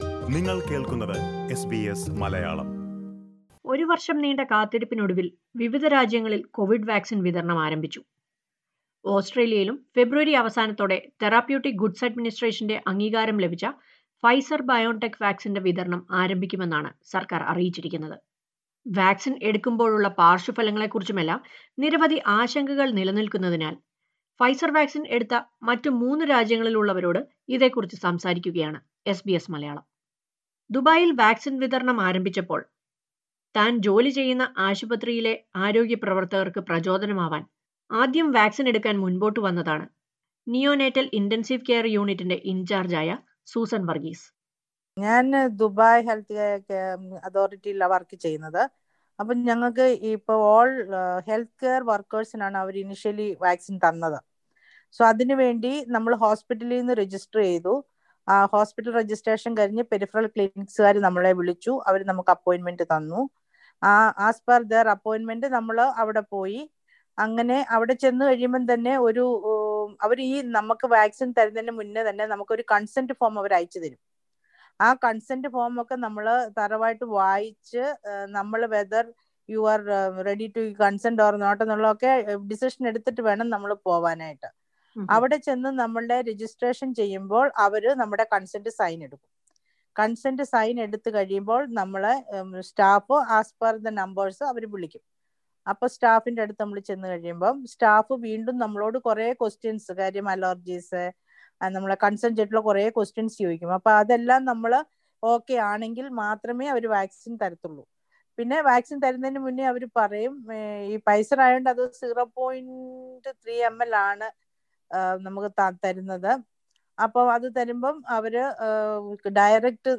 Listen to Malayalees from three different countries who have already taken the Pfizer vaccine. They share their experiences on how the vaccine was administered and what all reactions they had after being injected.